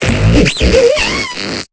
Cri de Moufflair dans Pokémon Épée et Bouclier.